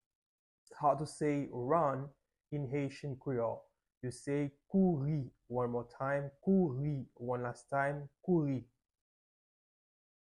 Pronunciation:
14.How-to-say-Run-in-Haitian-Creole-–-kouri-with-pronunciation.mp3